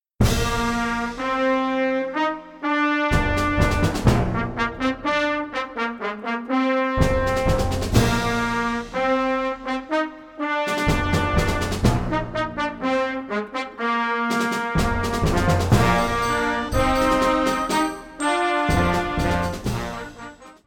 Catégorie Harmonie/Fanfare/Brass-band
Sous-catégorie Musique de concert